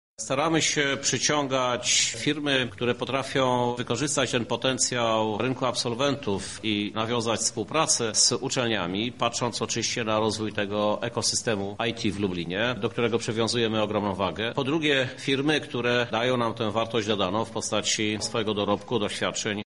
-To wielka szansa dla naszego Lublina – mówi prezydent miasta Krzysztof Żuk.